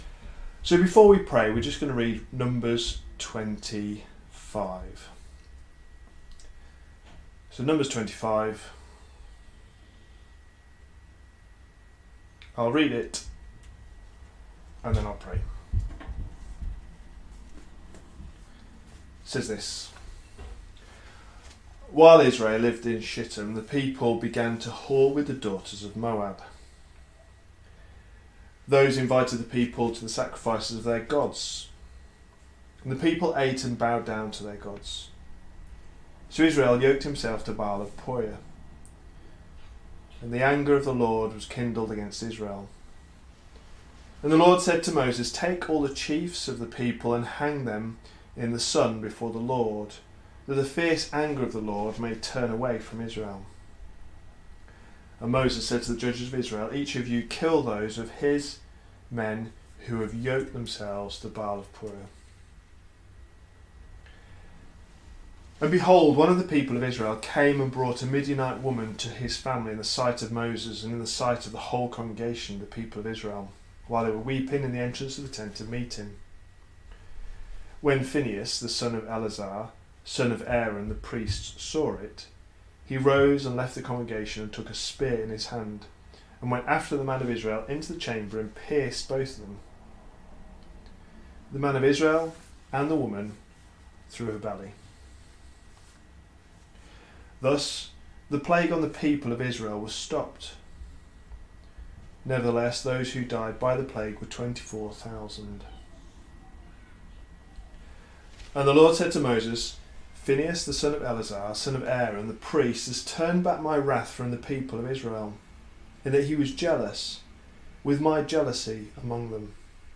A sermon preached on 24th January, 2021, as part of our Numbers series.